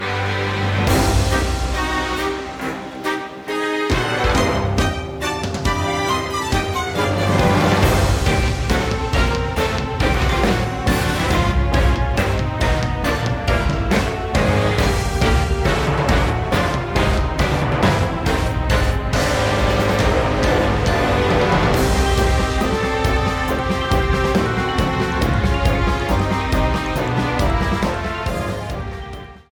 Ripped from the game
trimmed to 29.5 seconds and faded out the last two seconds